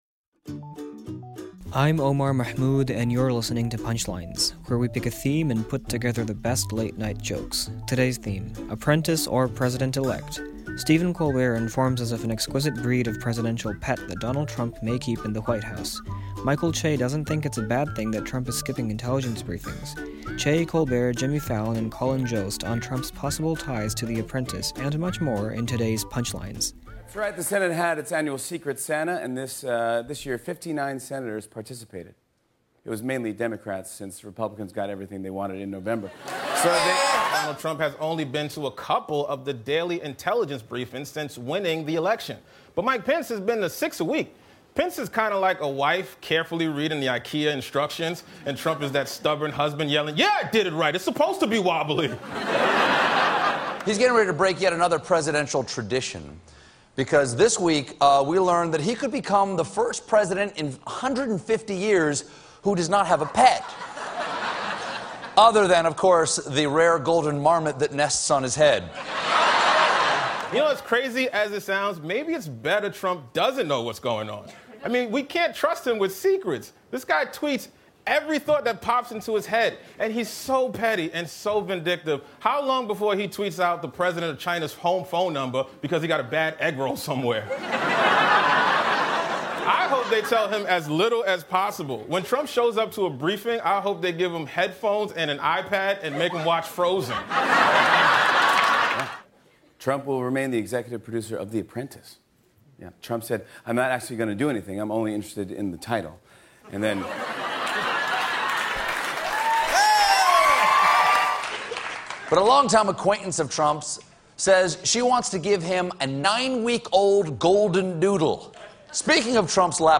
The late-night comics on Trump's interest in television but apparent lack of interest in the presidency.